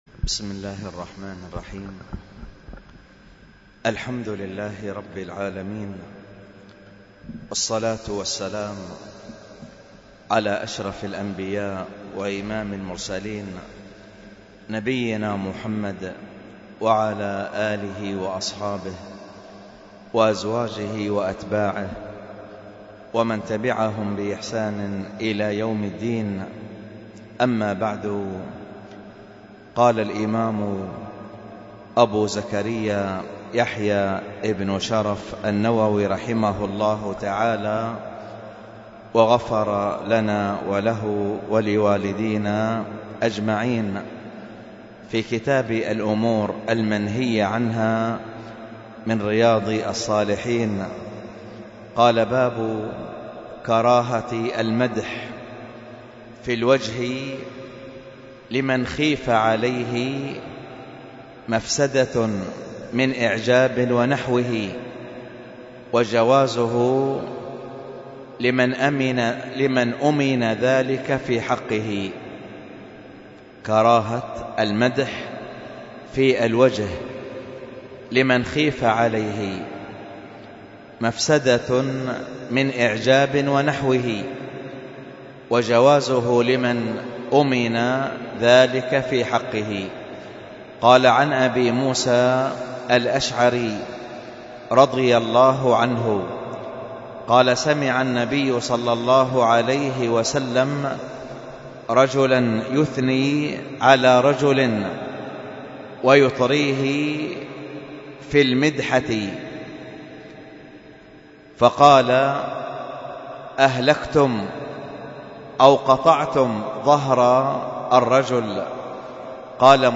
الدرس في شرح مختصر السيرة 120، الدرس العشرون بعد المائة:من( ذكر ردة بني سليم ...